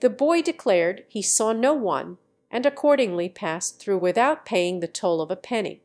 Matcha-TTS - [ICASSP 2024] 🍵 Matcha-TTS: A fast TTS architecture with conditional flow matching